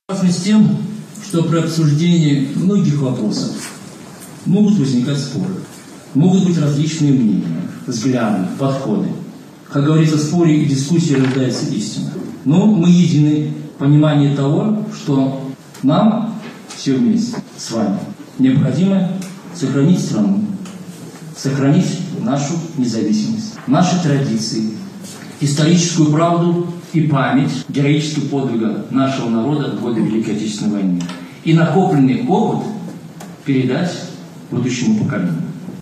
Масштабное мероприятие под девизом «Единство. Истина. Будущее» с участием делегации нашего города, Барановичского, Ляховичского, Ивацевичского, Ганцевичского районов состоялось на базе Барановичского государственного университета.